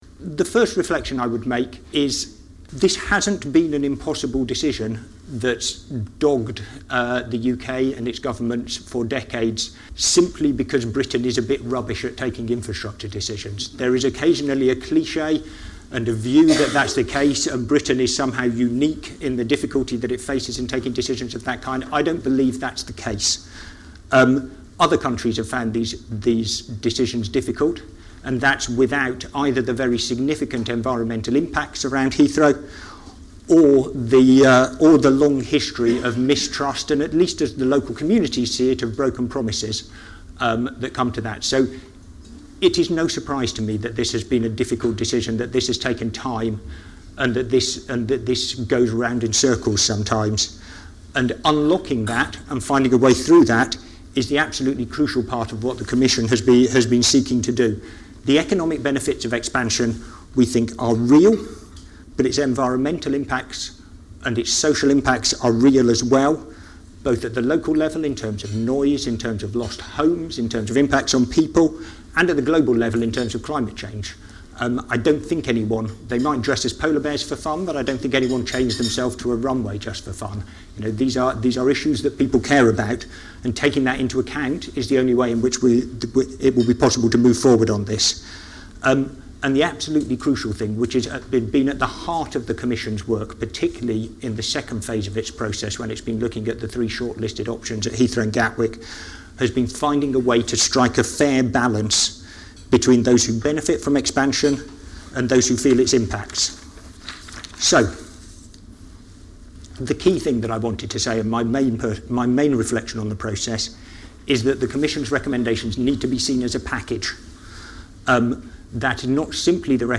The event was hosted by CBRE.